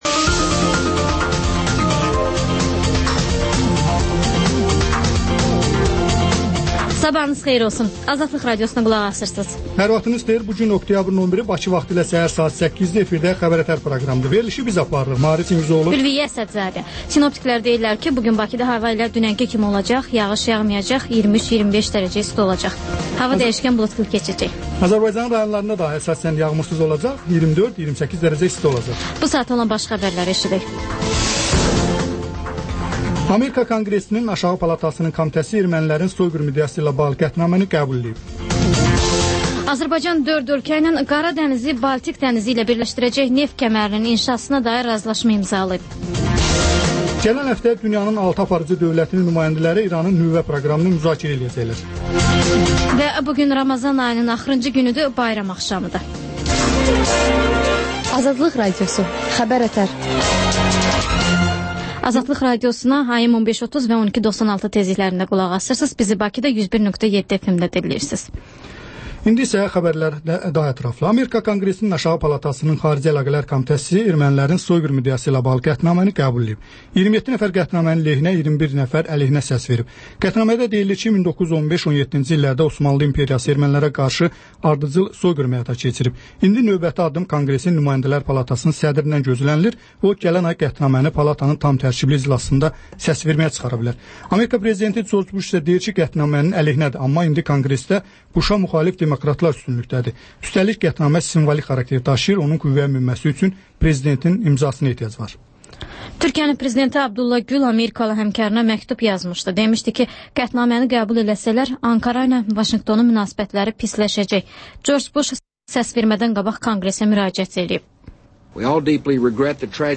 Xəbər-ətər: xəbərlər, müsahibələr, sonda 14-24: Gənclər üçün xüsusi veriliş